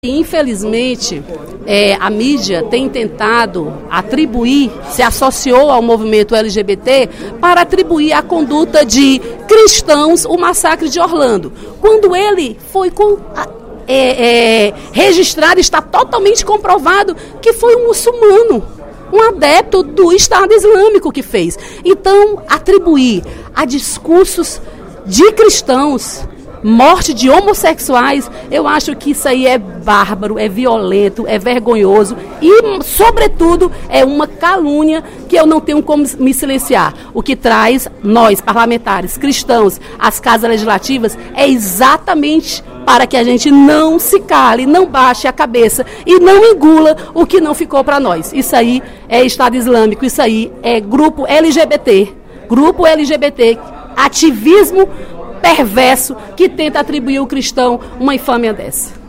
A deputada Dra. Silvana (PMDB) criticou, durante o primeiro expediente da sessão plenária desta terça-feira (14/06), a repercussão da imprensa à chacina na boate Pulse, em Orlando, Estados Unidos, onde aproximadamente 50 pessoas foram mortas por um atirador.